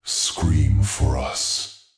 Subject description: A very electronic infantry unit voice!